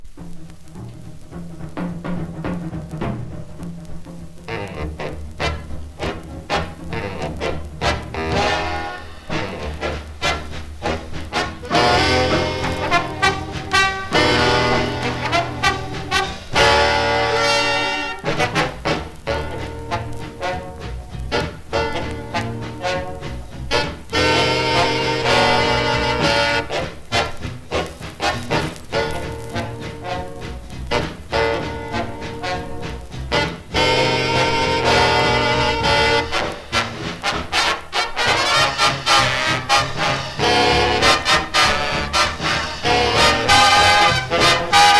軽やかさとナイトクラブの喧騒を感じるようなミッド・テンポな楽曲がずらり。
Jazz, Big Band, Swing　USA　12inchレコード　33rpm　Mono